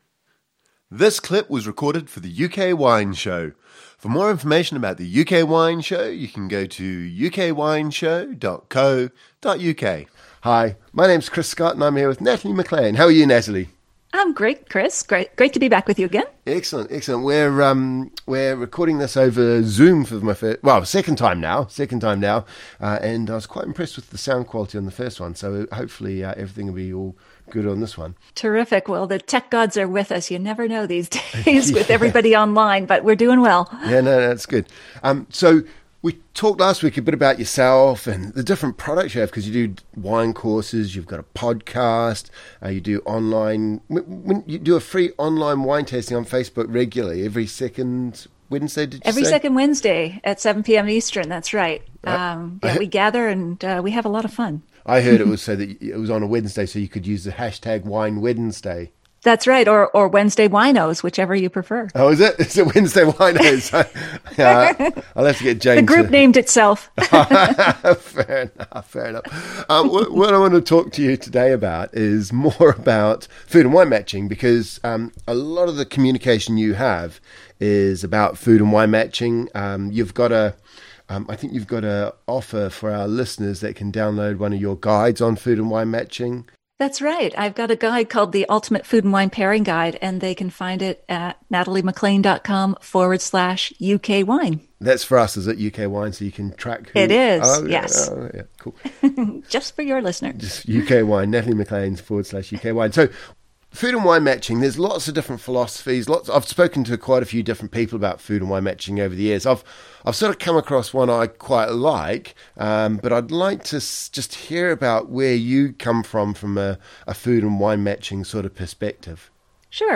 Overview In this second interview we zoom in on food & wine pairing.